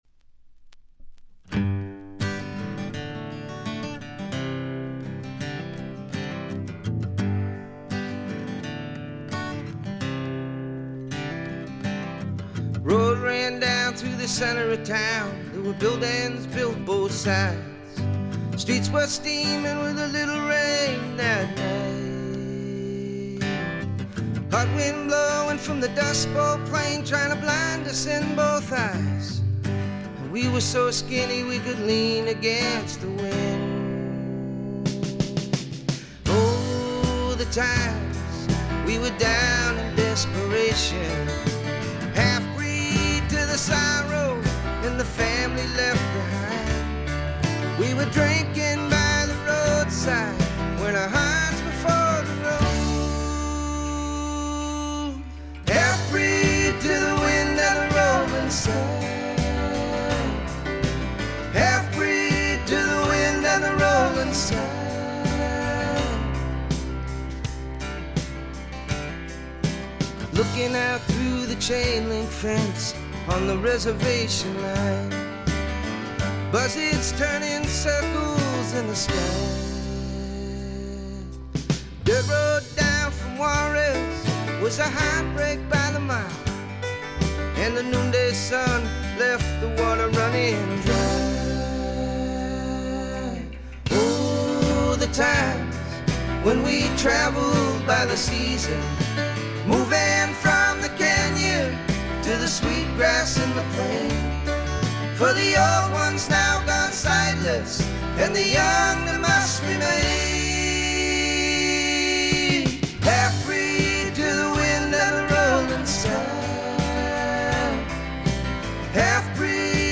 主に静音部で軽微なチリプチ（A3序盤では目立ちます）。
ボブ・ディランのフォロワー的な味わい深い渋い歌声はそのままに、むしろ純度がより上がった感があります。
試聴曲は現品からの取り込み音源です。